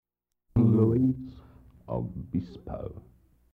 Wire [station] ID, 10 seconds
Form of original Audiocassette